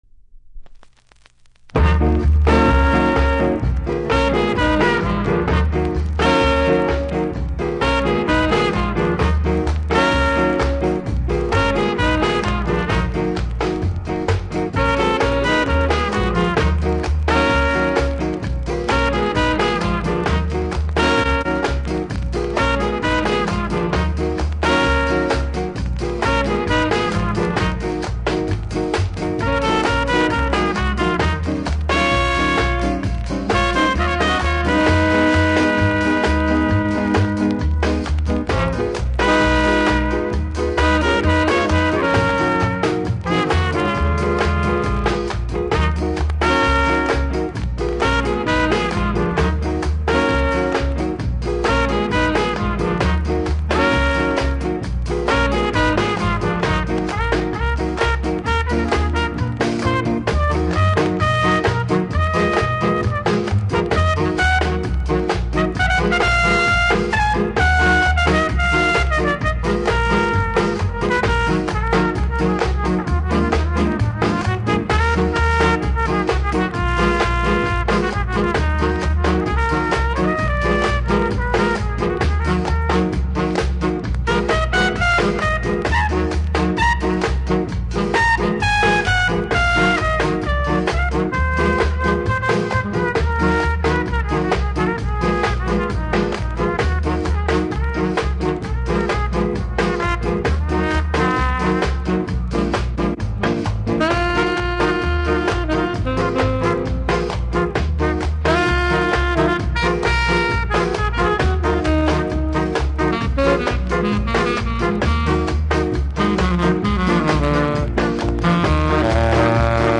多少のノイズ気にならない方ならプレイ可レベル！